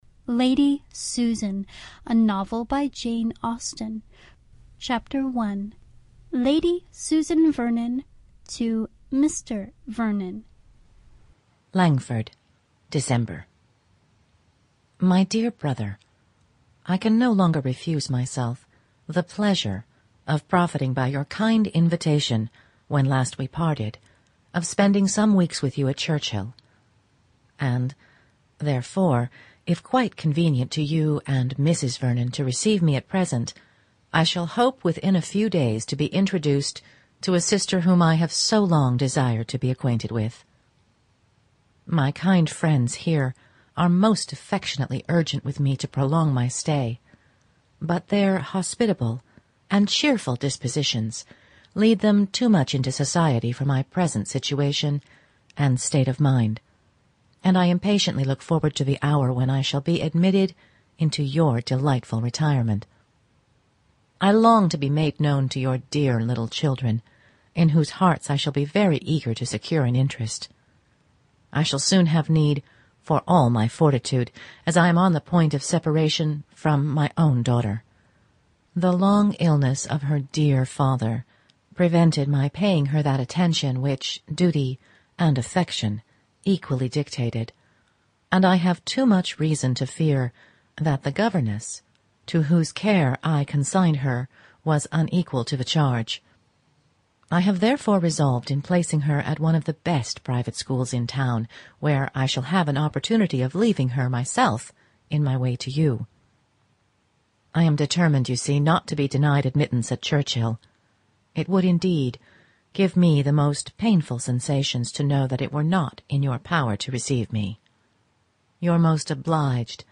Lady Susan By Jane Austen | Original And Classic Audiobook